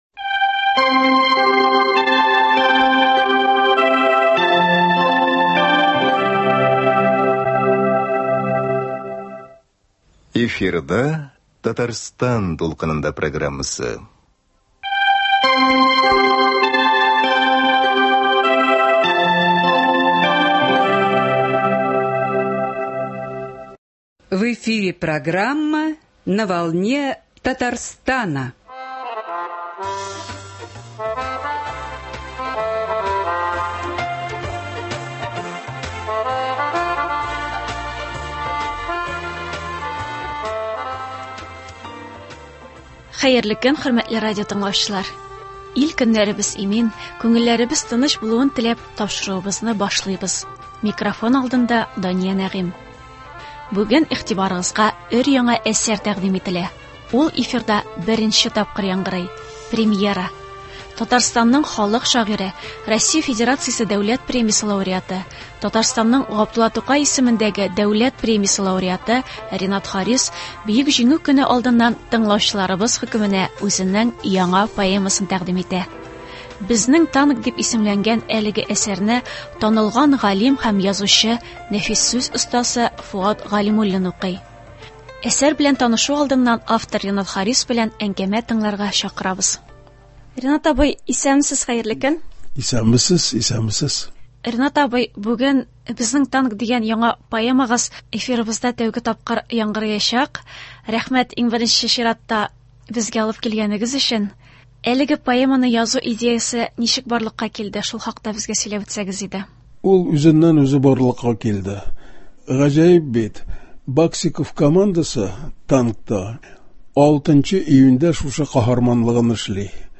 Ул эфирда беренче тапкыр яңгырый.
Әсәр белән танышу алдыннан автор Ренат Харис белән әңгәмә тыңларга чакырабыз.